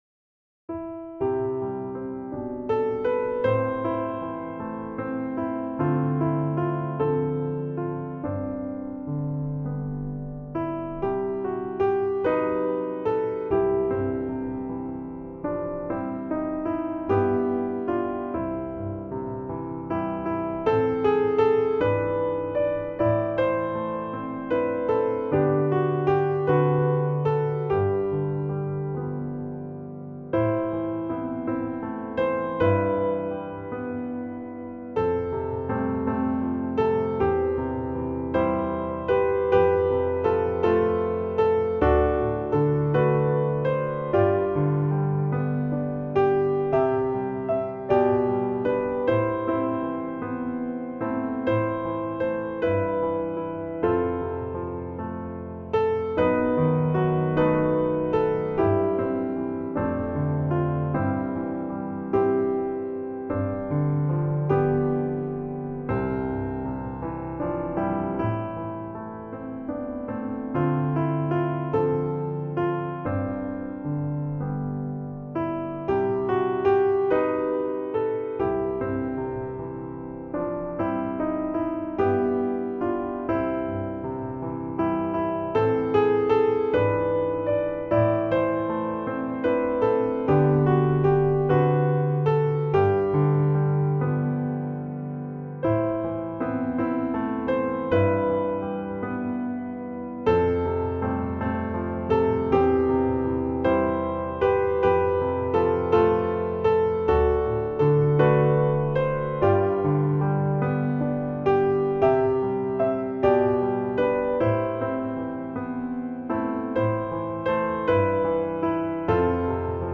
Voicing/Instrumentation: Primary Children/Primary Solo
Great lyrics and catchy tune.